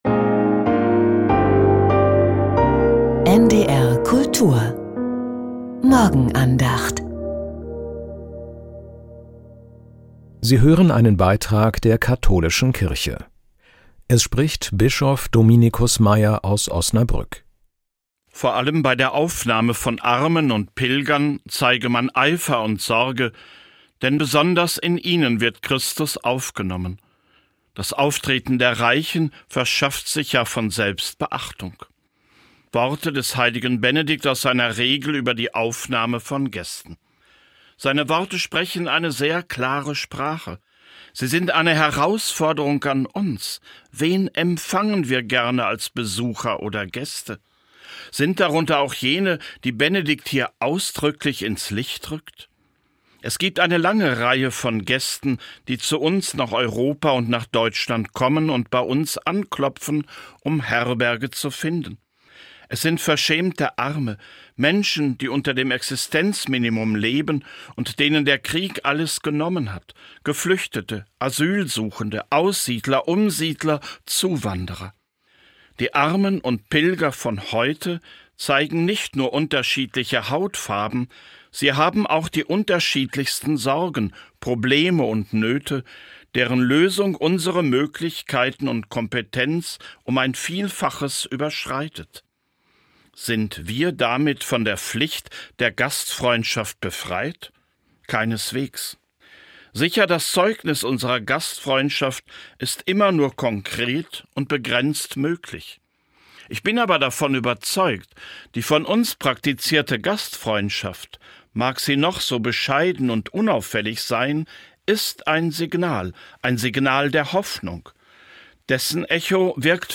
Verschaffe den Armen Beachtung ~ Die Morgenandacht bei NDR Kultur Podcast
Bischof Dominicus Meier.